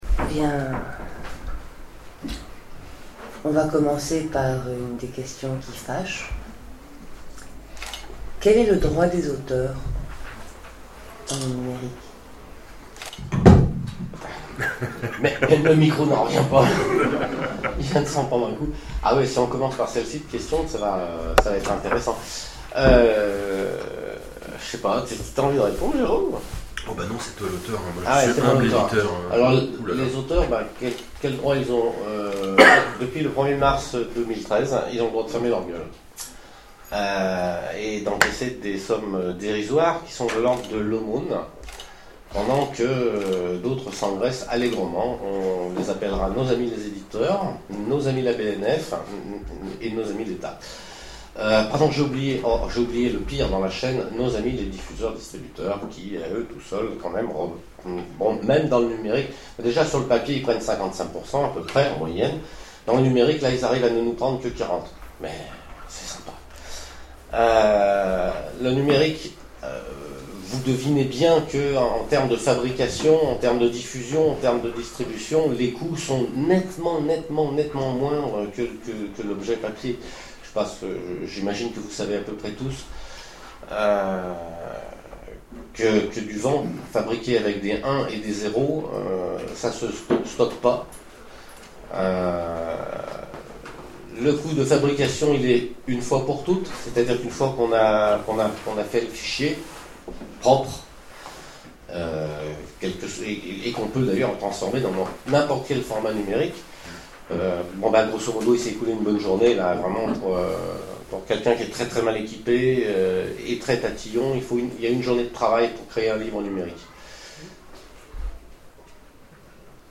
Oniriques 2013 : Conférence La quête numérique